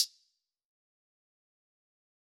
Closed Hats
DAHI PERSONAL HIHAT.wav